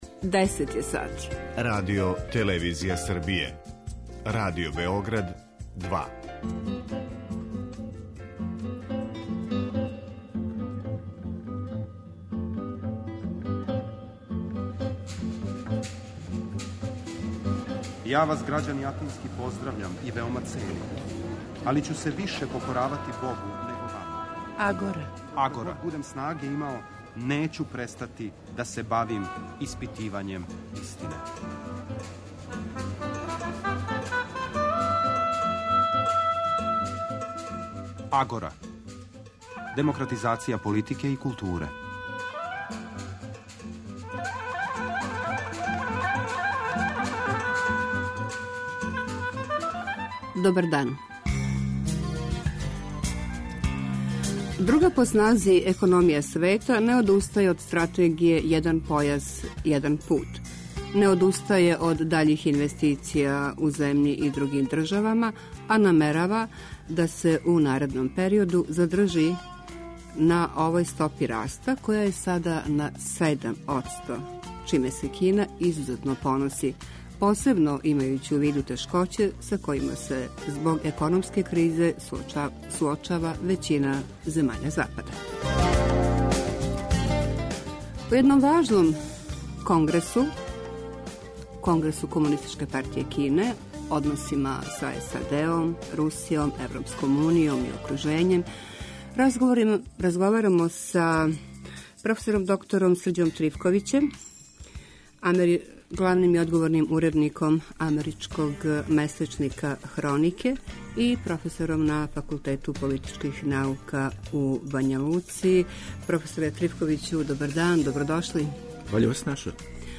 Радио-магазин